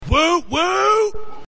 whoopwhoop.mp3